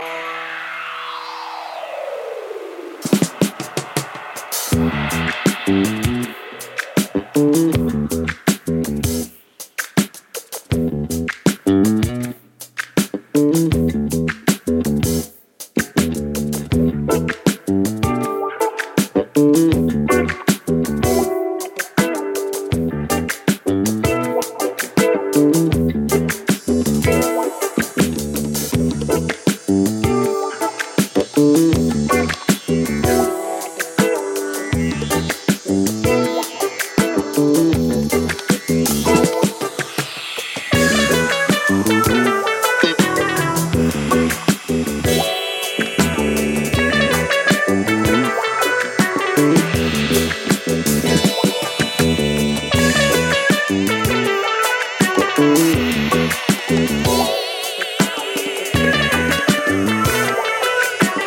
disco, jazz-funk, African rhythms